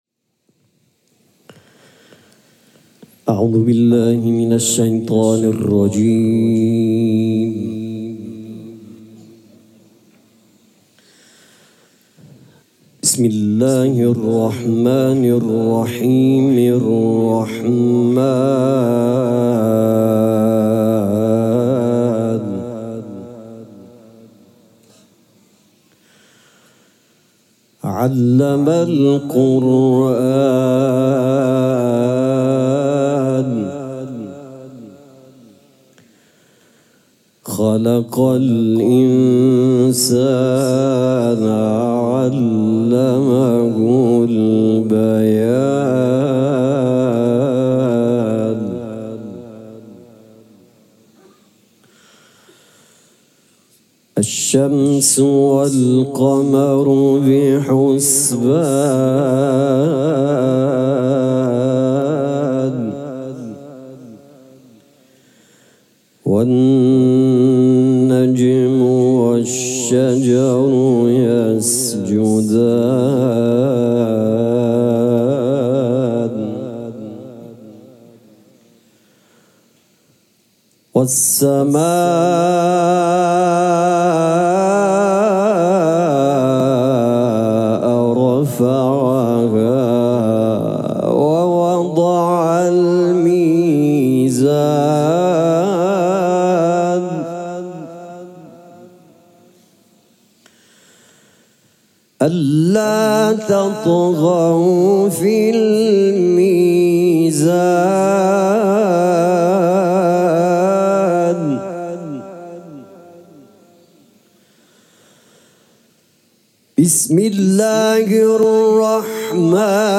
مراسم عزاداری شام غریبان محرم الحرام ۱۴۴۷
قرائت قرآن